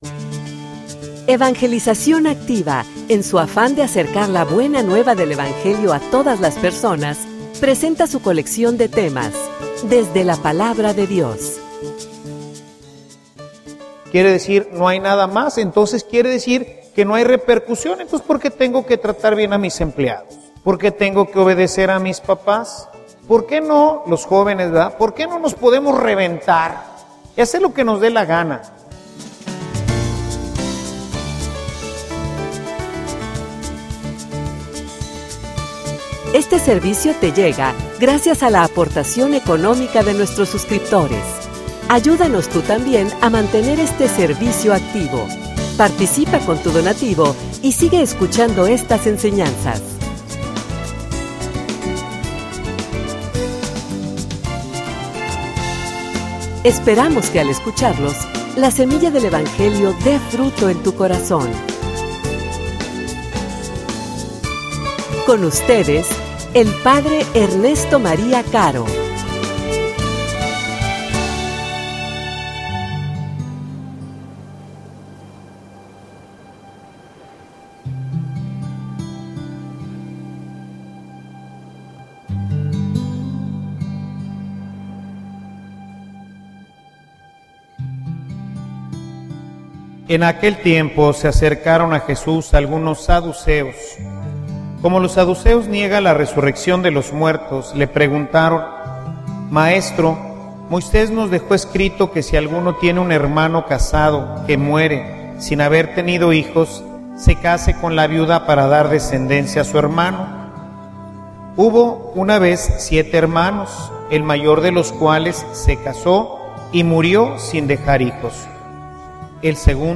homilia_El_muerto_al_pozo_y_el_vivo_al_gozo.mp3